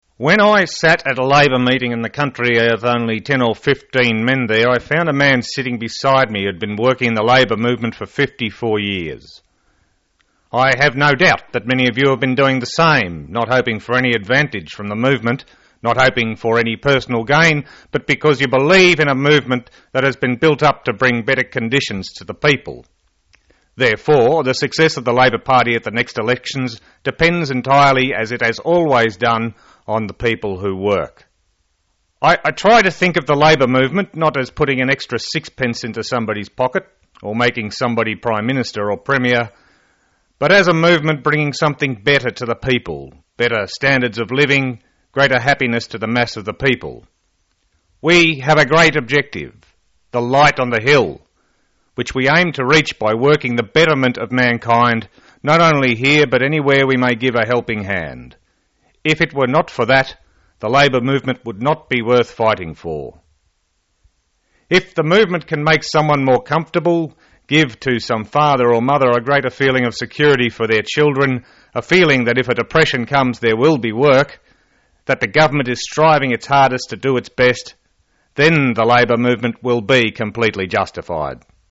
Australian Prime Minister Ben Chifley gave this speech to a meeting of the Australian Labour Party in 1949.
The recording is not the original, but a re-enactment from the series ‘Great Rural Speeches’ on ABC in 2007.